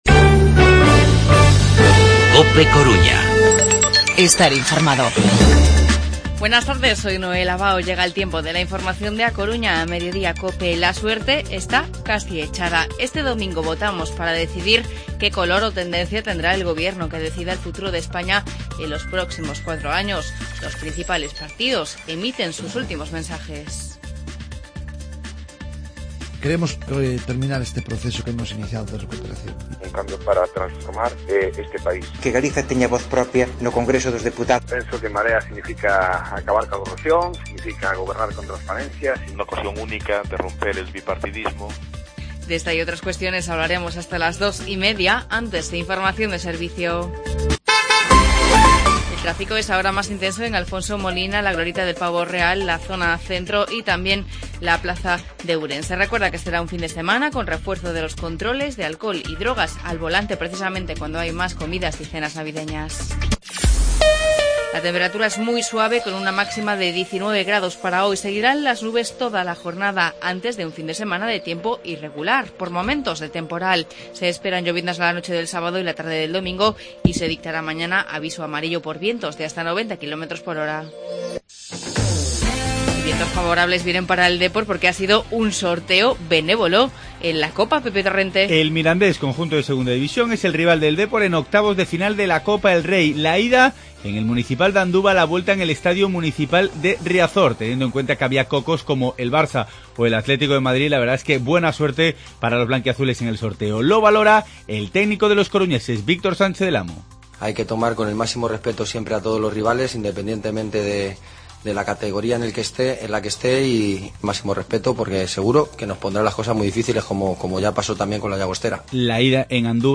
Informativo Mediodía COPE Coruña viernes, 18 de diciembre de 2015